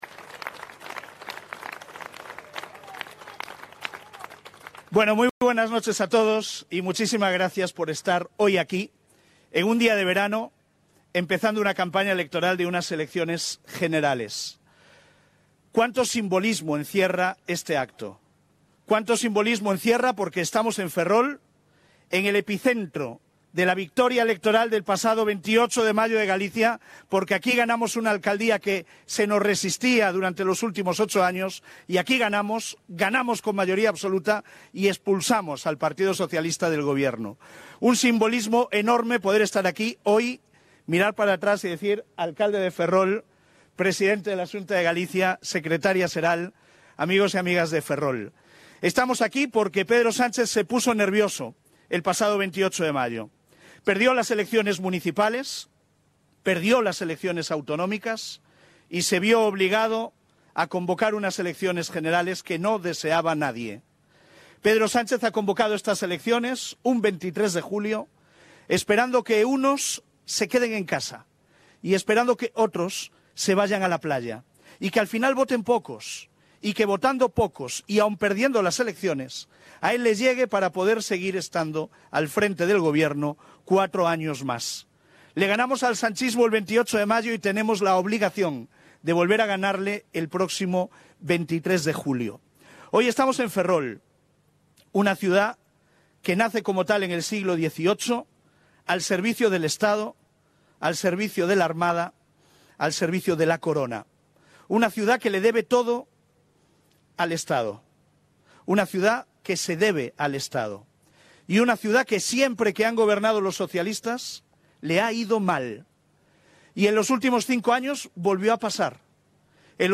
Acto del PP de inicio de campaña de las elecciones generales en Ferrol - Galicia Ártabra Digital